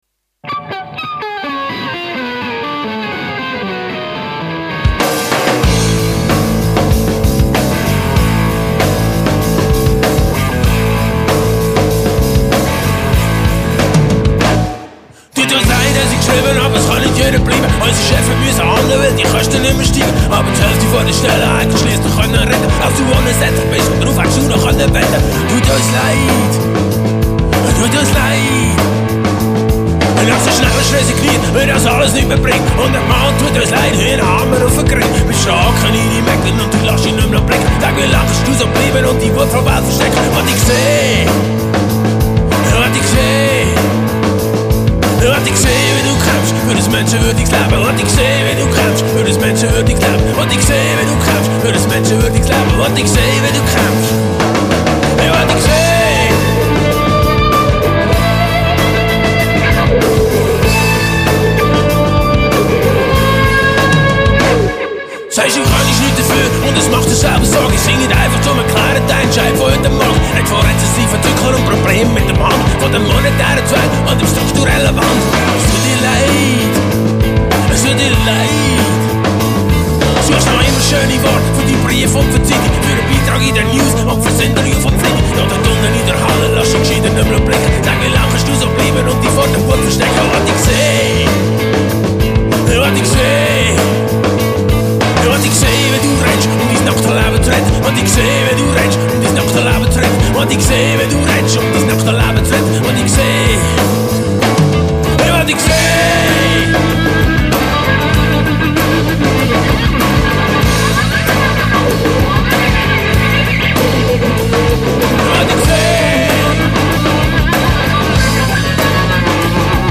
Ziemlich wild und retro, dieser Track.
guitars
drums
bass
vocals